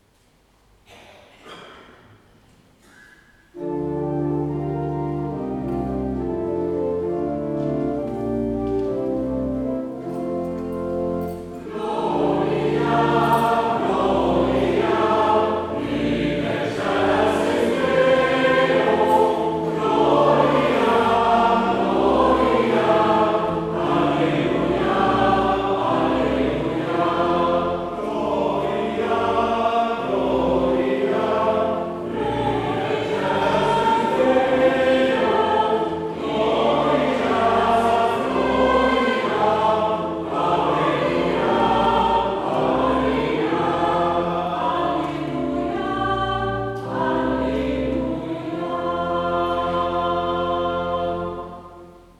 Heropening Sint-Pieterskerk Rotselaar